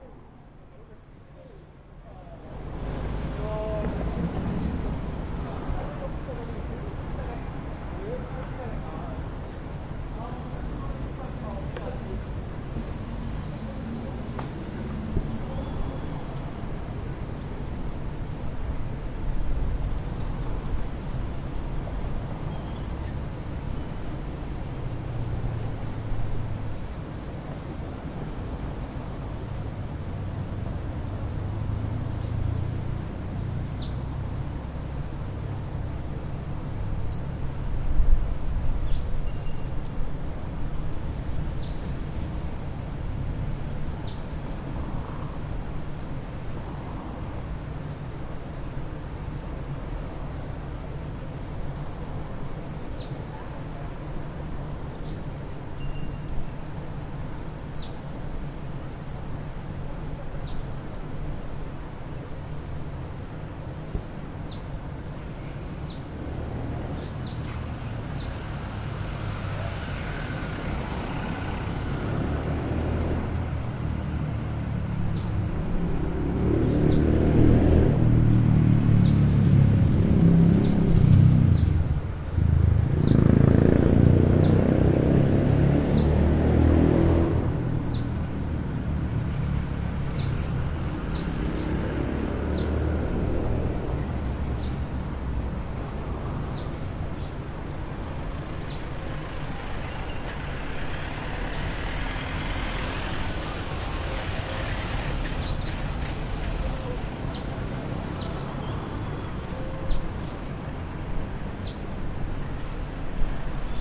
描述：记录停放区域，一些车辆经过。
Tag: 城市 停车 很多 喇叭 汽车